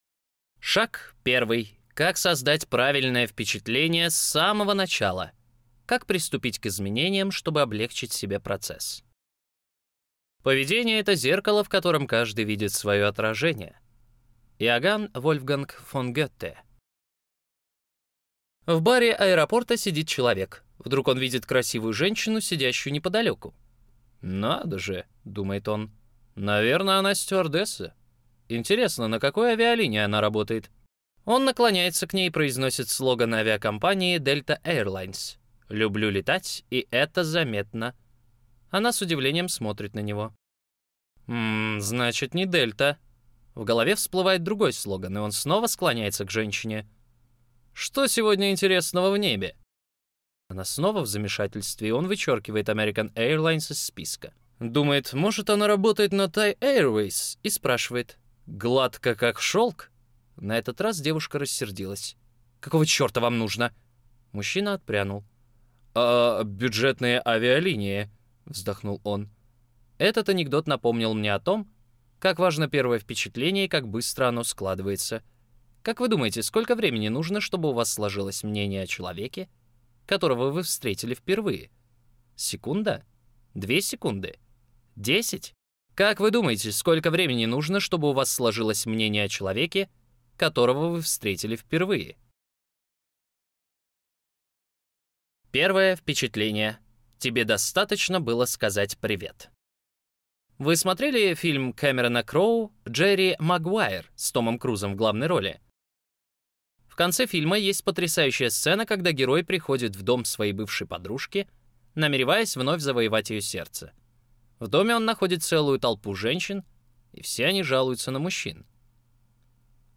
Аудиокнига Как изменить абсолютно все | Библиотека аудиокниг